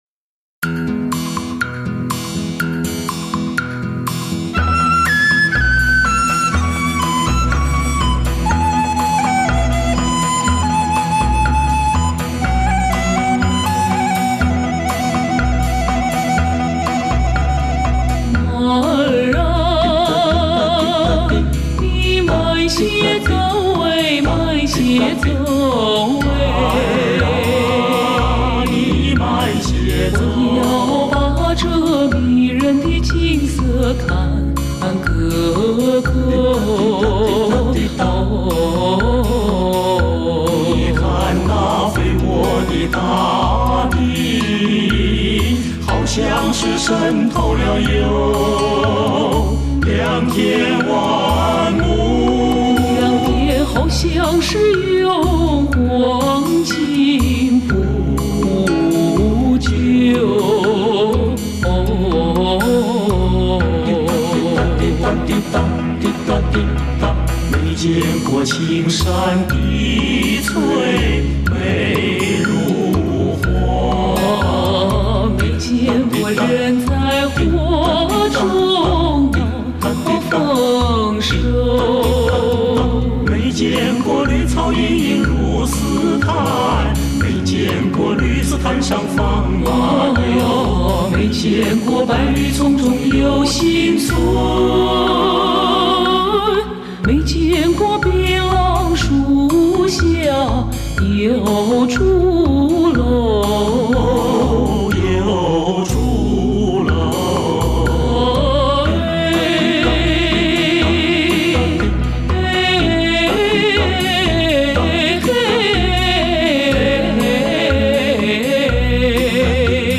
首首金曲 混音再造 顶级美国后期和声制作 人声与乐器结像清晰 立体鲜明
十五首精彩美妙和声经典 顶级发烧佳作